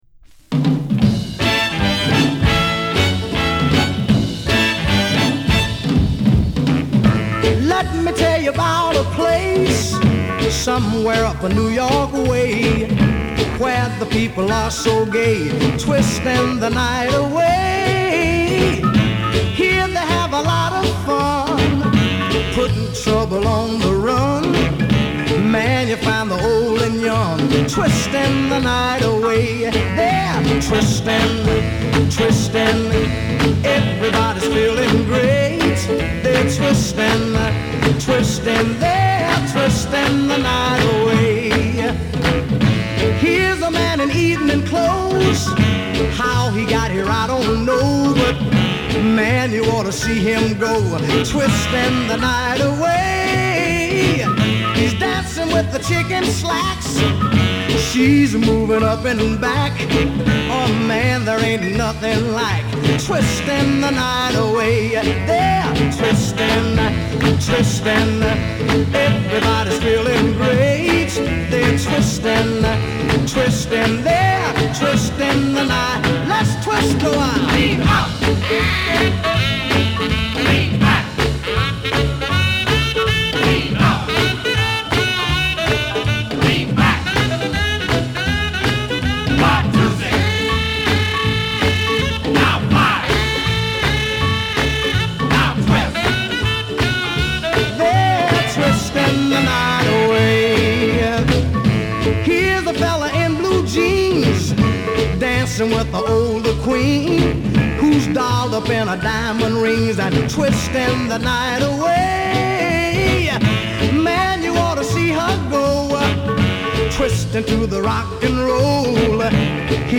グレート・ソウル・シャウター〜ダンサー。
[Original Song] [Mod/Classic] [NEW]